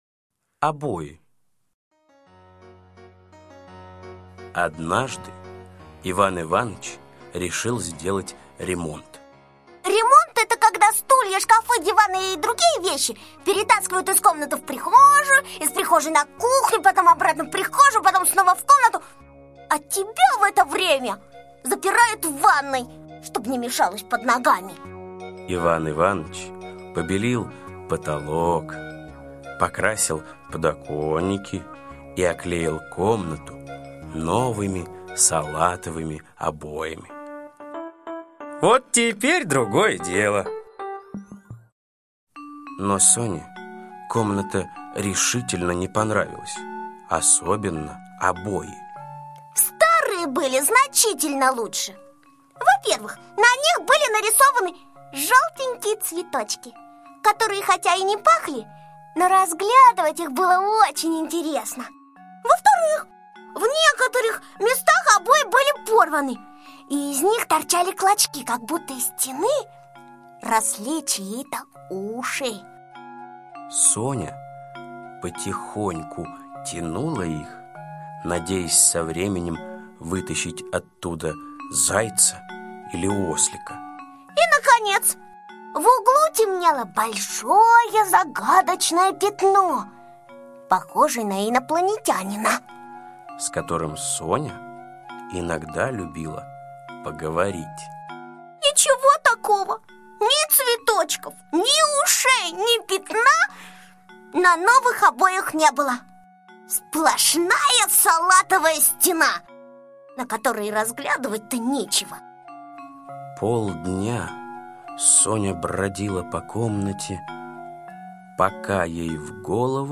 Обои - аудиосказка Усачева А.А. Хозяин Сони поклеил в комнате новые обои, но Соне они показались скучными, и она решила их украсить.